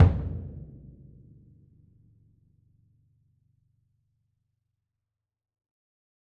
bdrumnew-hit-v7-rr1-sum-(1).mp3